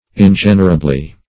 ingenerably - definition of ingenerably - synonyms, pronunciation, spelling from Free Dictionary Search Result for " ingenerably" : The Collaborative International Dictionary of English v.0.48: Ingenerably \In*gen"er*a*bly\, adv. In an ingenerable manner.
ingenerably.mp3